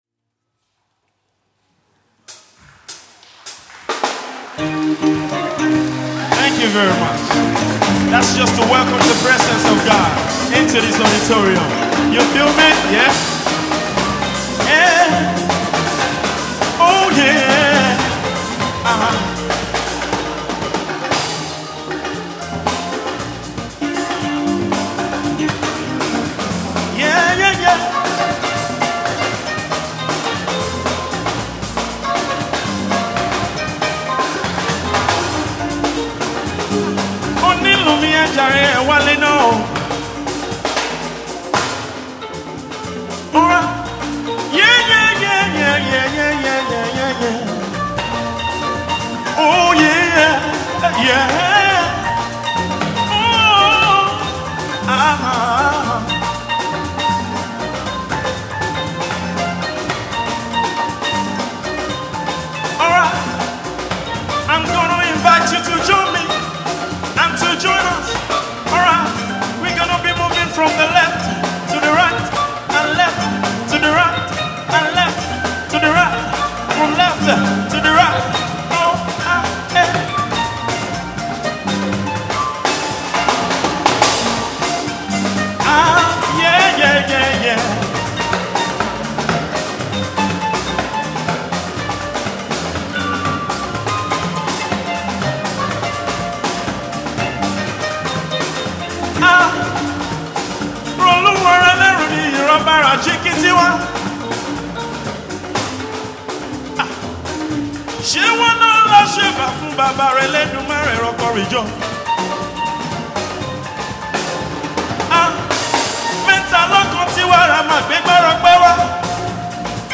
African Community Gospel Choir Competition